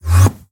Minecraft Version Minecraft Version 1.21.4 Latest Release | Latest Snapshot 1.21.4 / assets / minecraft / sounds / mob / endermen / portal2.ogg Compare With Compare With Latest Release | Latest Snapshot